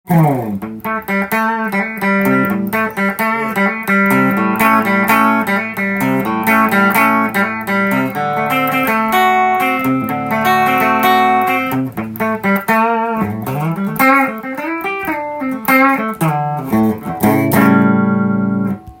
試しに弾いてみました
ネックがかなり太く、音もギブソンと同じような太さがしました。
クリーントーンで弾いても太さを満喫できますが